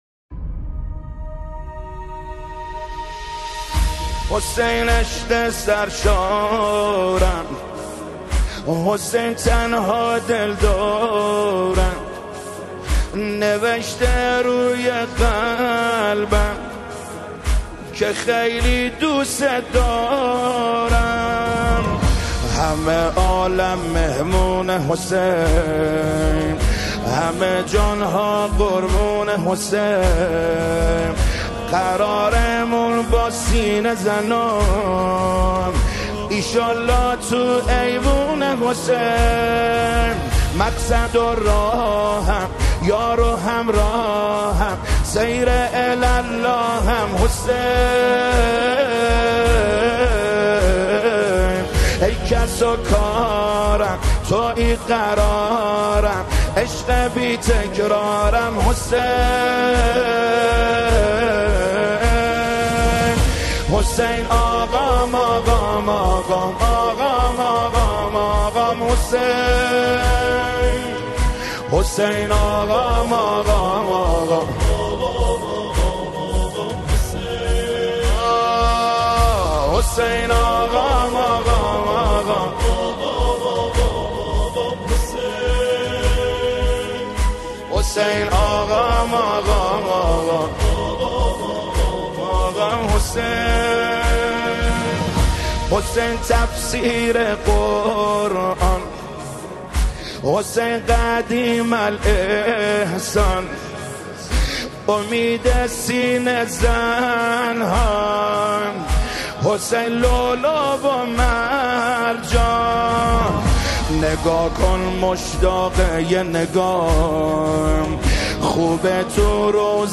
نماهنگ دلنشین